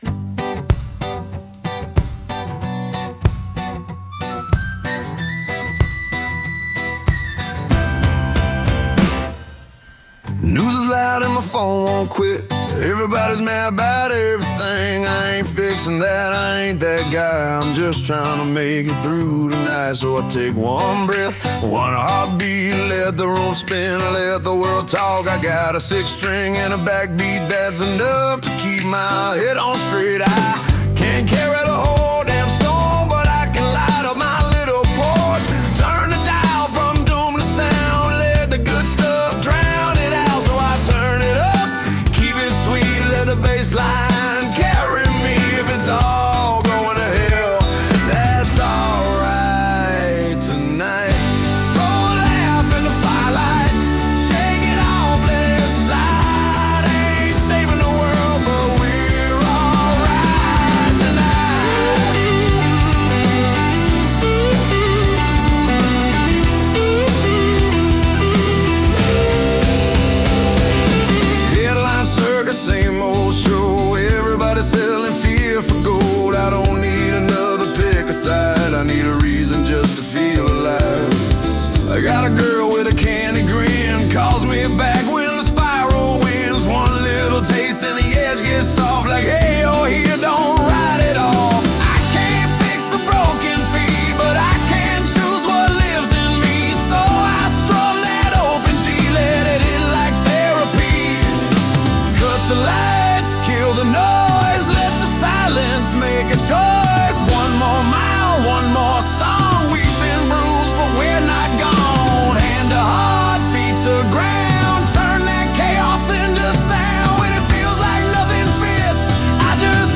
Key: G
Tempo: ~94 BPM
Time: 4/4
Vibe: country-punk × reggae-rock